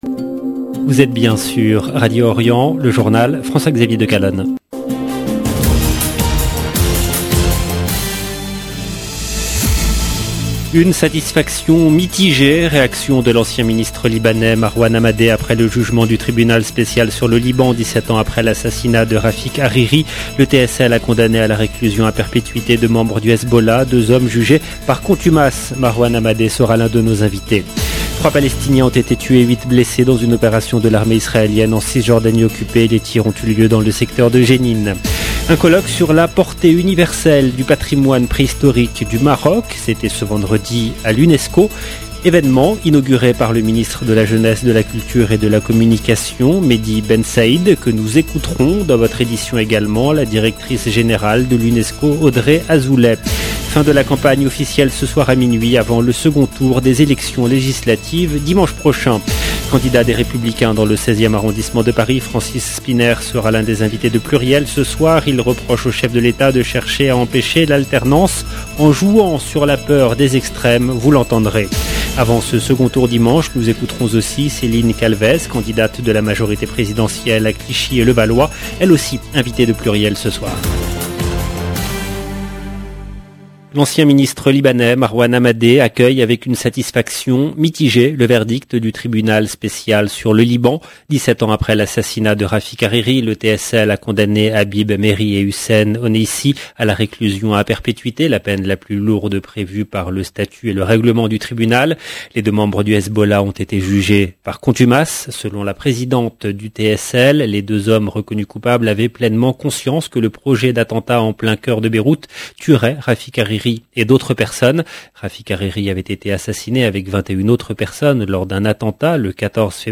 EDITION DU JOURNAL DU SOIR EN LANGUE FRANCAISE DU 17/6/2022